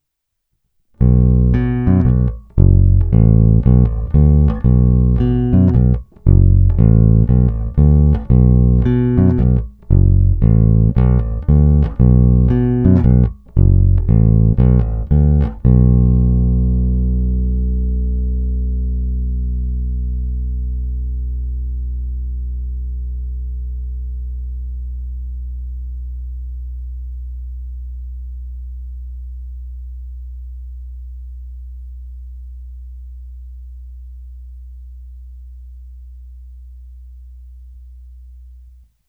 Není-li uvedeno jinak, následující nahrávky jsou provedeny rovnou do zvukové karty a s plně otevřenou tónovou clonou. Nahrávky jsou jen normalizovány, jinak ponechány bez úprav.
Snímač u krku